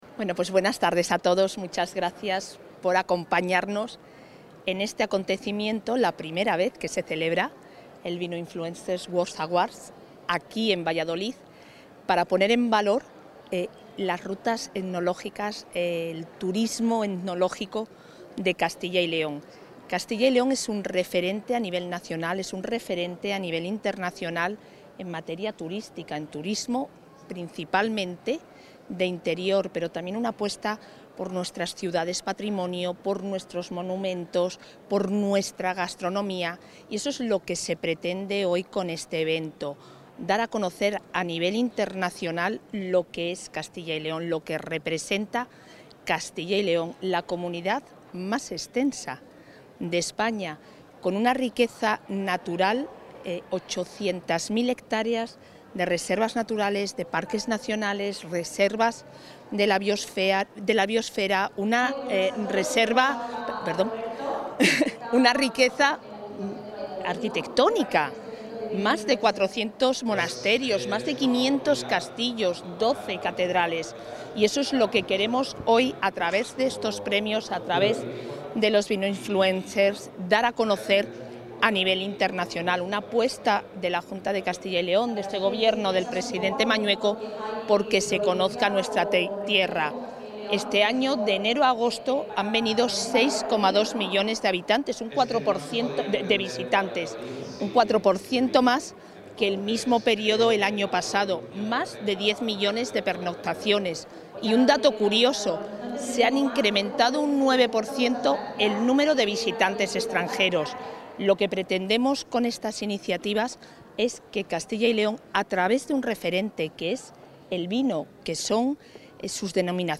Intervención de la vicepresidenta.
El Centro Cultural Miguel Delibes de Valladolid acoge la I Gala ‘Vinoinfluencers World Awards’, una iniciativa apoyada y financiada por la Junta de Castilla y León donde se han dado cita 150 comunicadores del mundo del vino, procedentes de 26 países, para reconocer su labor de difusión del sector, a través de galardones en diez categorías.